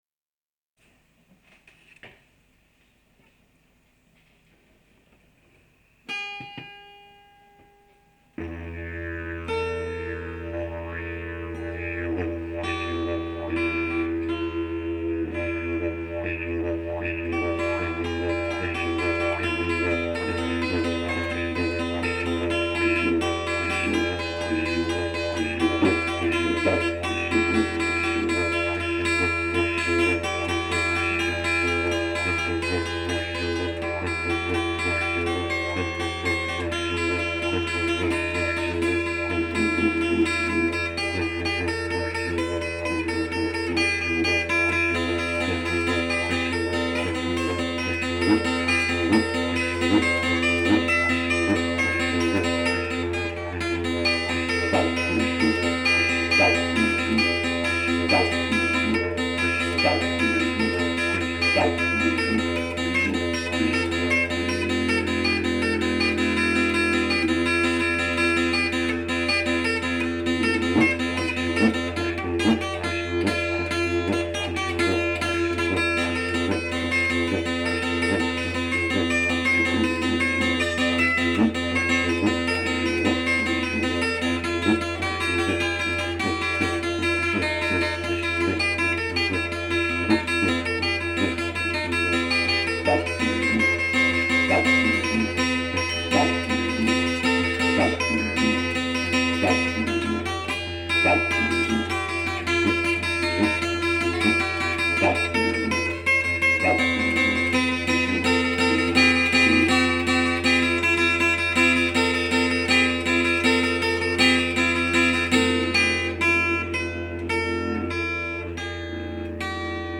didj + guitare (09.06.05)